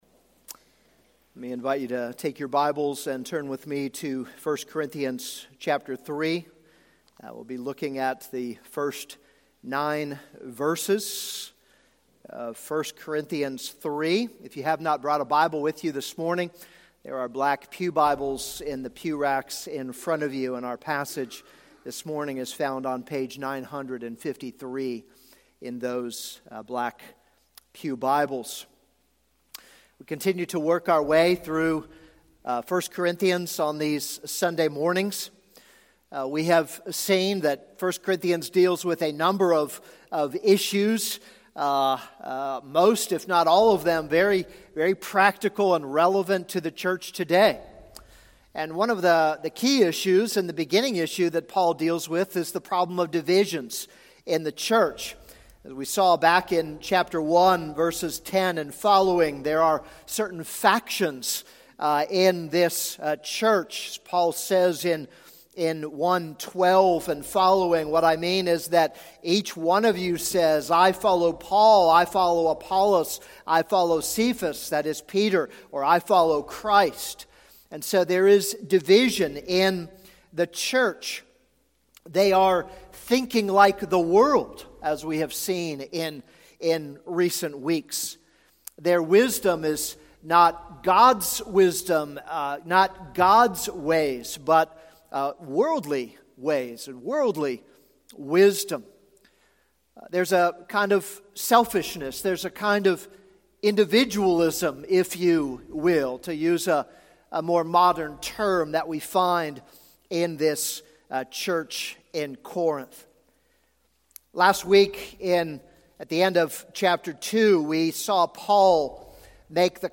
This is a sermon on 1 Corinthians 3:1-9.